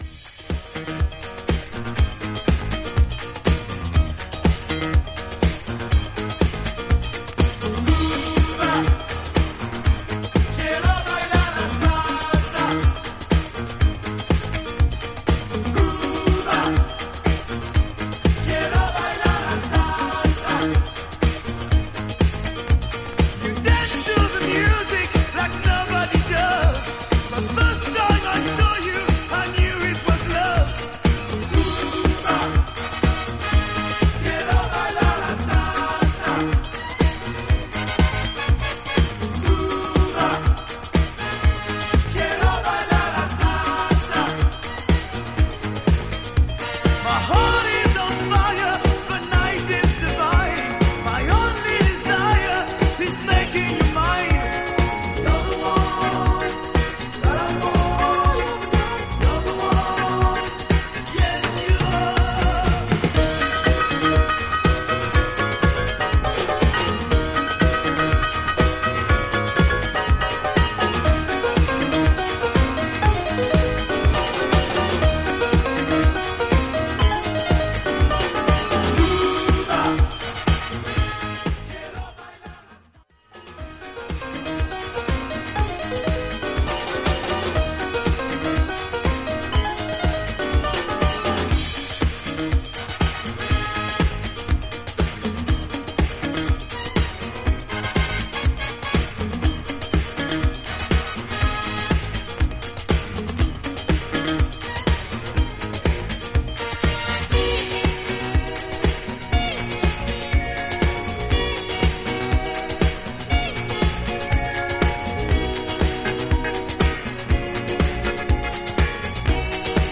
灼熱のラテン・ディスコ・チューン